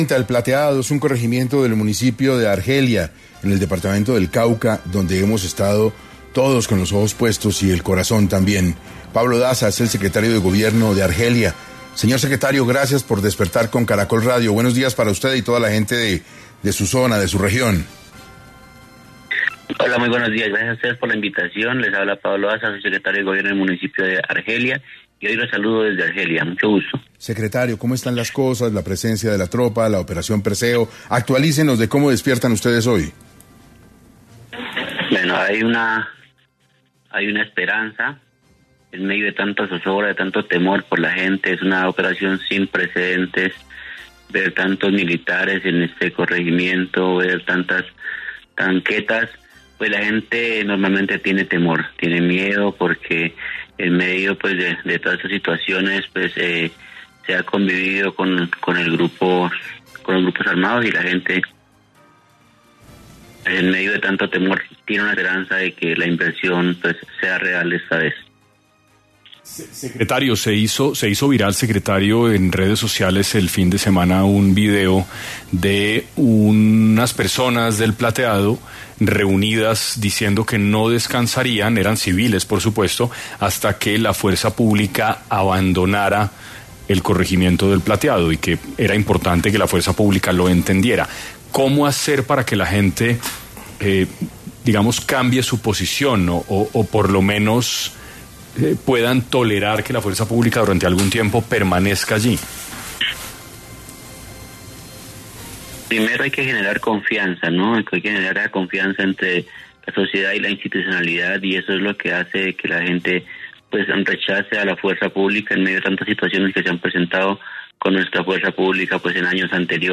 Pablo Daza, secretario de Gobierno de Argelia, habló en 6AM sobre cómo está la situación de orden público en Argelia tras la puesta en marcha de la Operación Perseo